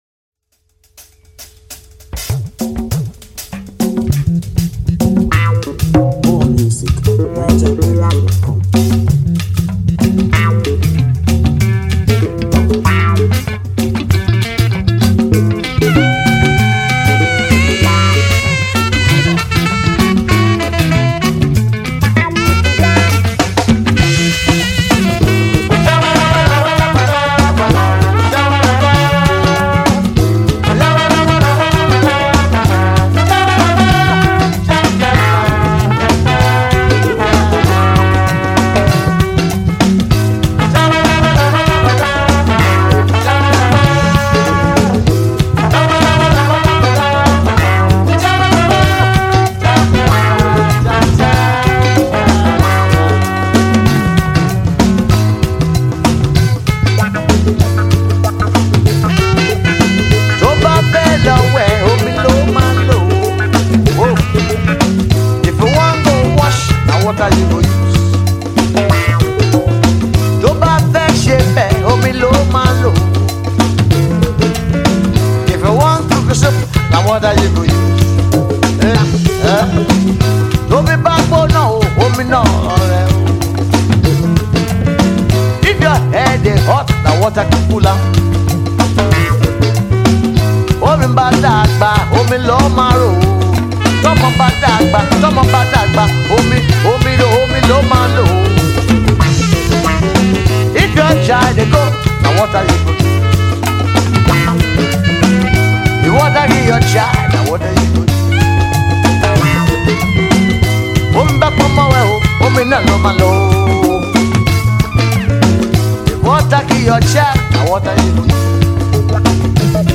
Home » Ragae
Wonderful Reggae Music